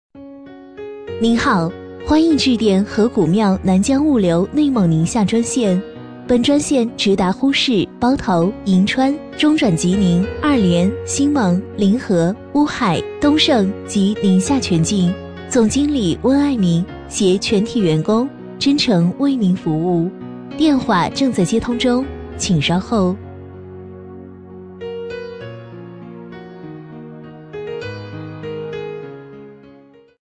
A类女50
【女50号彩铃】河古庙南江物流
【女50号彩铃】河古庙南江物流.mp3